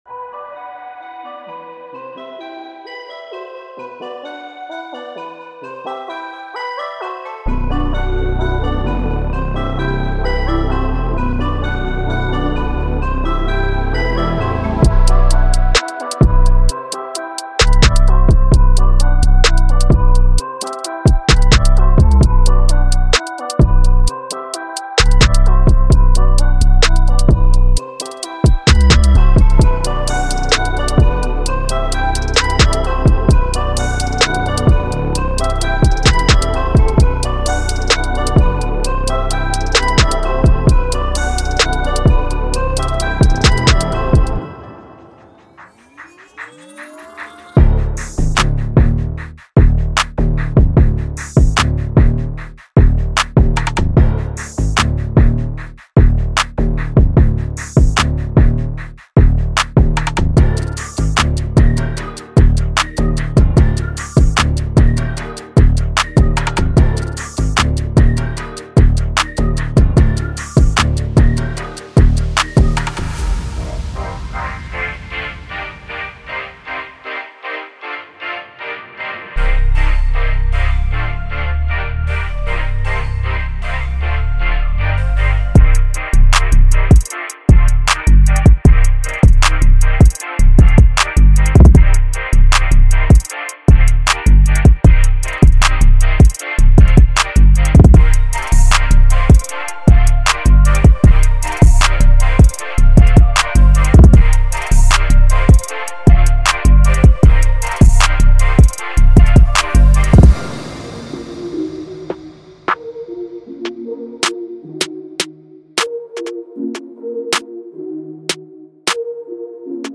A complete collection of 200 Hip Hop and R&B Serum presets featuring ground-shaking 808’s, unique basses, diverse keys, catchy leads, dreamy pads, crisp plucks and more.